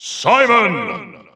The announcer saying Simon's name in English releases of Super Smash Bros. Ultimate.
Simon_English_Announcer_SSBU.wav